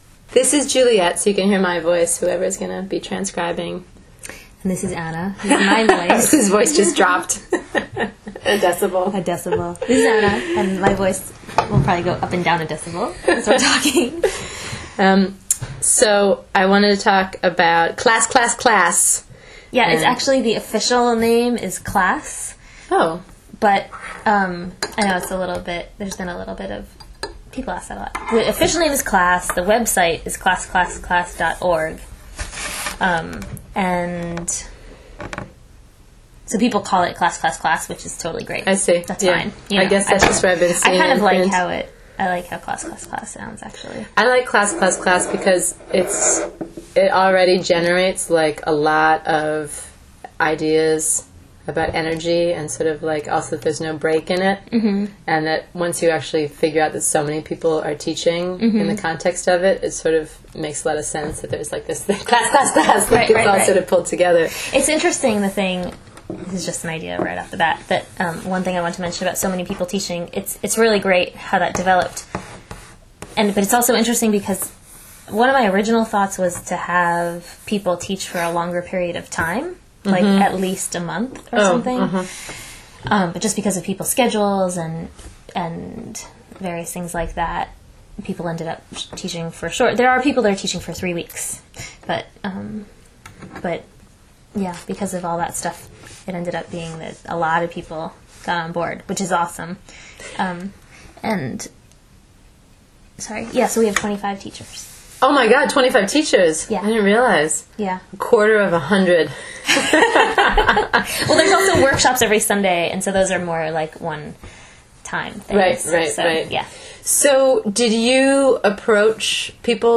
Download this conversation as a Podcast